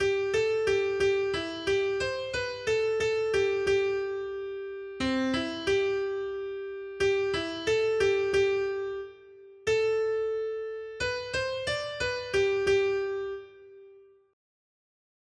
responsoriální žalm